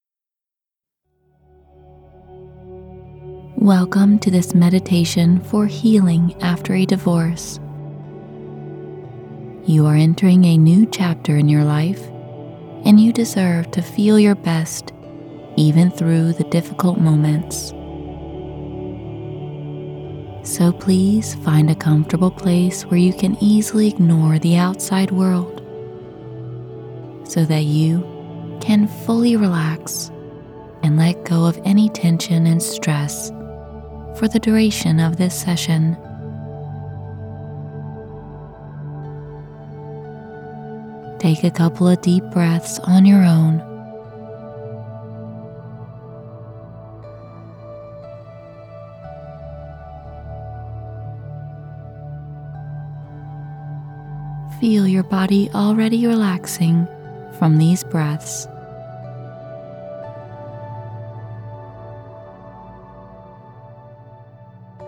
This Audio Hypnosis session is designed for anyone experiencing a hard time recovering from divorce.